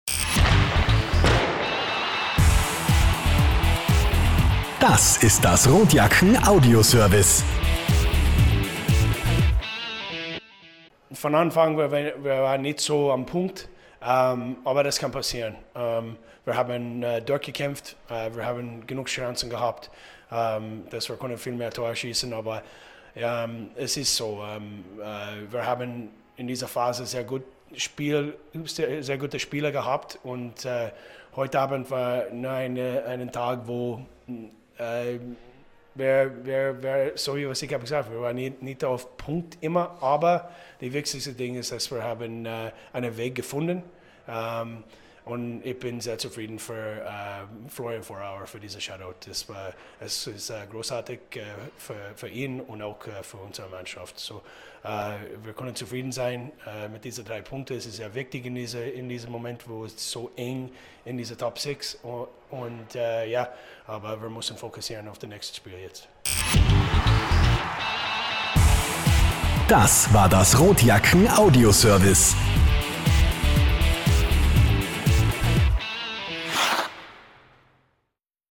Post Game (KAC-ASH)
Heidi Horten-Arena, Klagenfurt, AUT, 4.237 Zuschauer